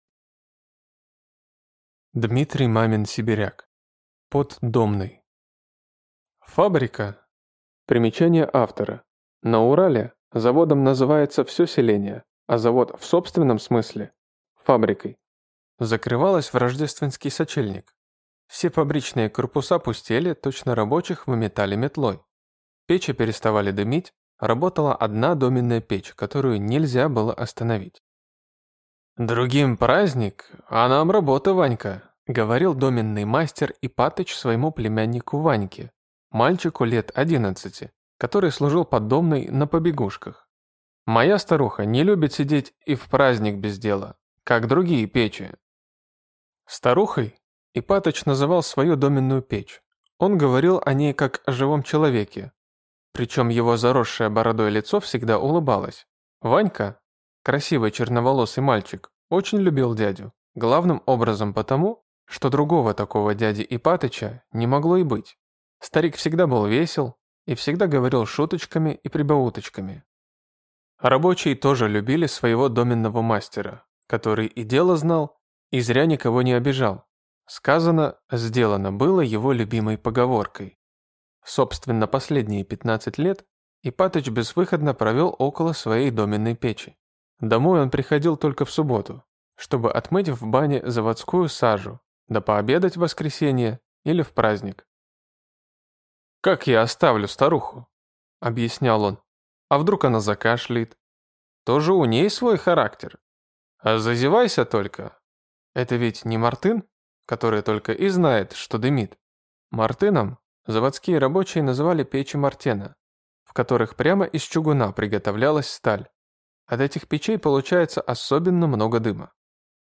Аудиокнига Под домной | Библиотека аудиокниг
Прослушать и бесплатно скачать фрагмент аудиокниги